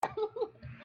Palo Laugh